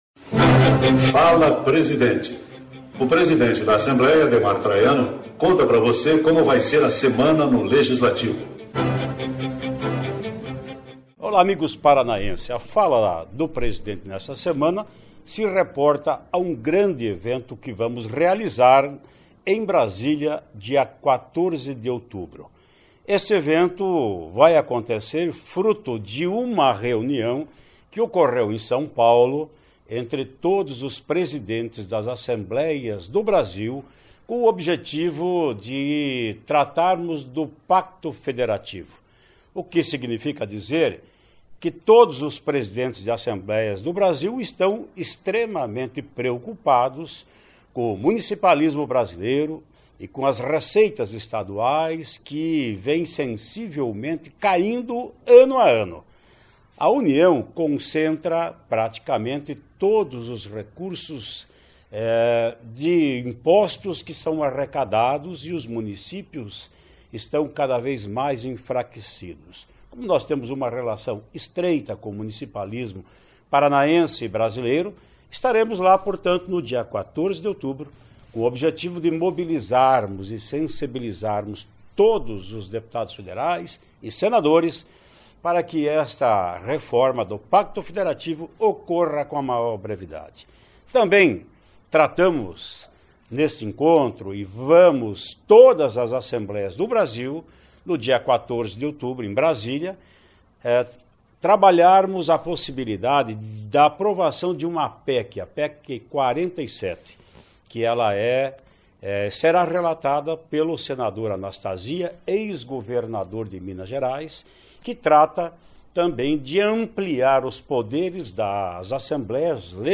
No Fala Presidente desta semana Ademar Traiano adianta dois temas que serão discutidos em um encontro entre todas as assembleias do país, em Brasília, no dia 14 de outubro: o pacto federativo e a ampliação dos poderes do legisaltivo.